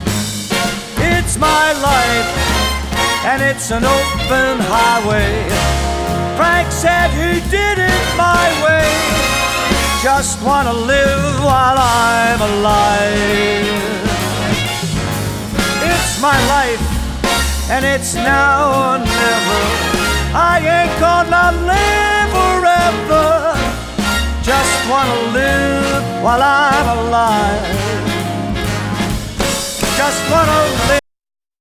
Här kommer ett looptest av DA > mikrofonförstärkare > AD.
Filerna är nivåmatchade med gainkontrollen på mickförstärkarens ingång.
Mikrofonförstärkaren är Line Audio 8MP, omvandlare är Lynx Aurora 8.